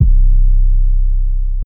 808 (Use This Gospel).wav